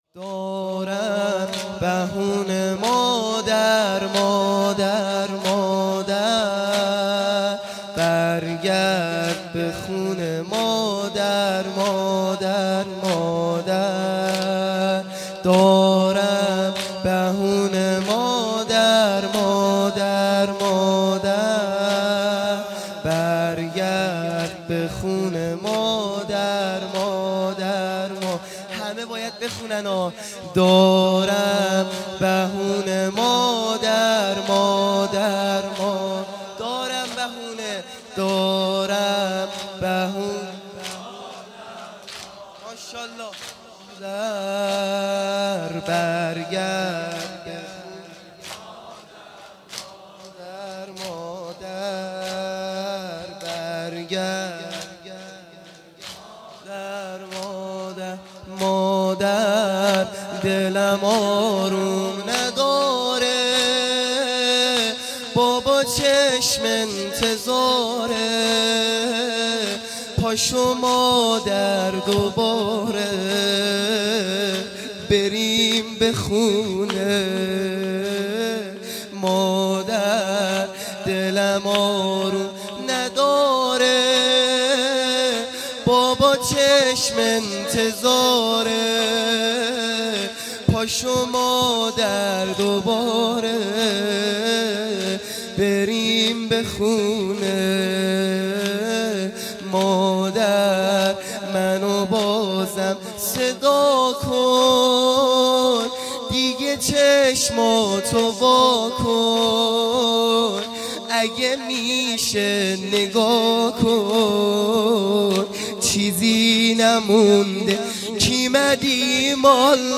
زمینه | دارم بهونه مادر
فاطمیه دوم(شب سوم)